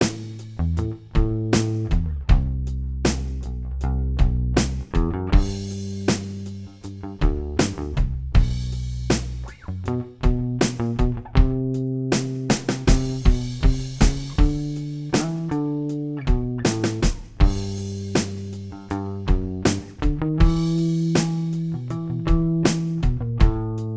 Minus All Guitars Pop (1980s) 3:20 Buy £1.50